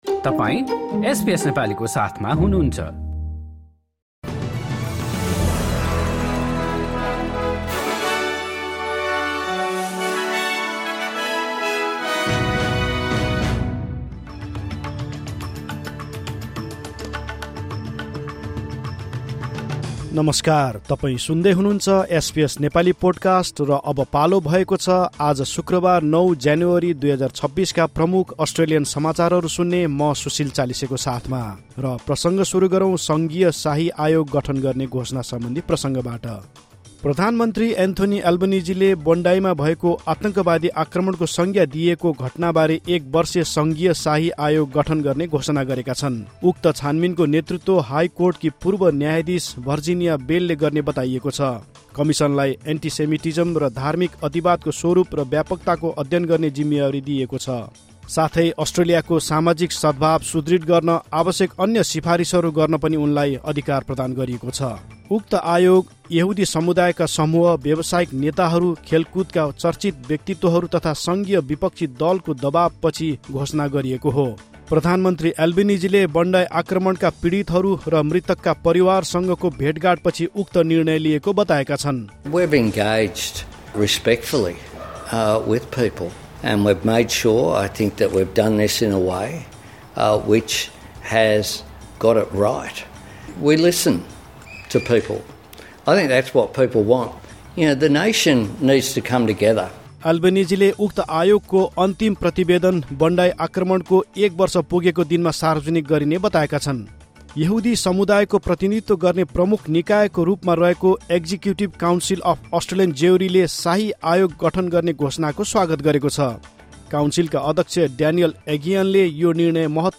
एसबीएस नेपाली प्रमुख अस्ट्रेलियन समाचार: शुक्रवार, ९ ज्यानुअरी २०२६